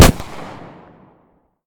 pistol-shot-03.ogg